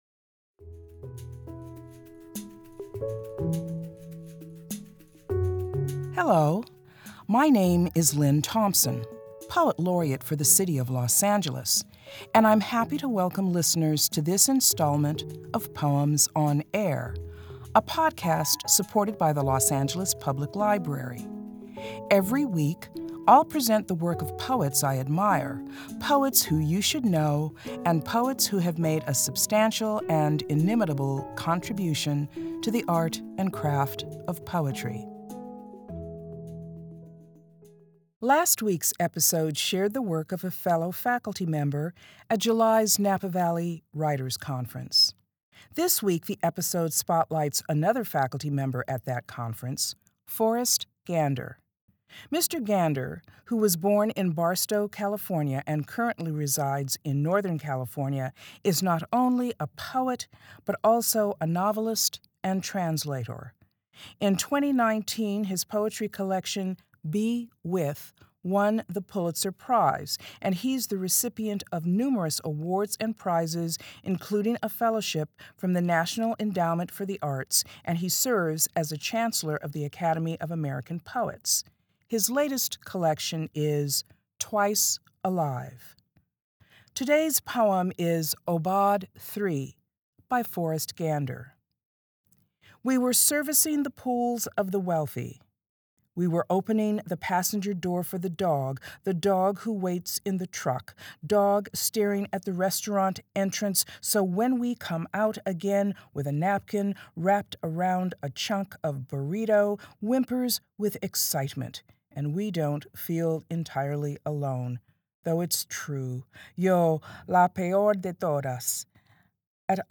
Los Angeles Poet Laureate Lynne Thompson reads Forrest Gander's poem "Aubade III."